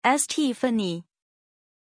Pronunciation of Stéphanie
pronunciation-stéphanie-zh.mp3